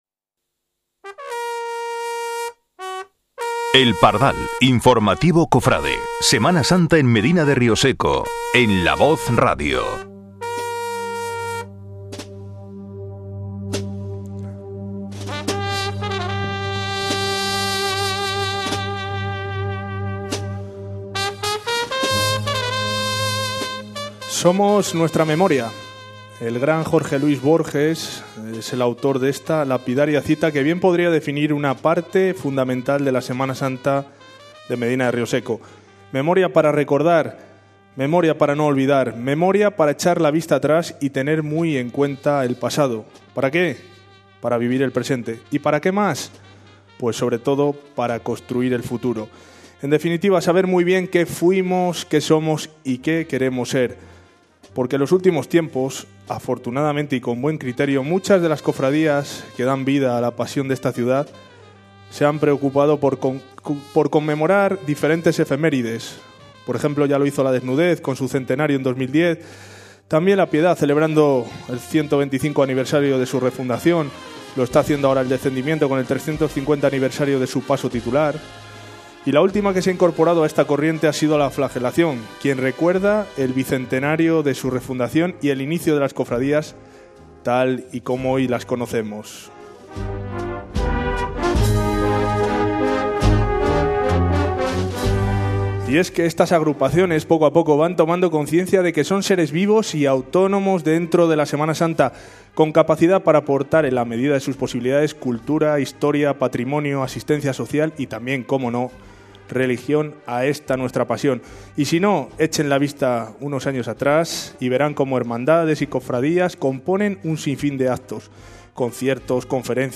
El Pardal es un programa radiofónico de La Voz de Rioseco por y para la Semana Santa.
Cogimos todos los cachivaches técnicos, salimos del estudio de La Voz y nos plantamos en la sala de exposiciones de Caja Mar, donde esta cofradía riosecana ha instalado una muestra fotográfica conmemorativa, donde se pueden disfrutar de algunos populares enseres.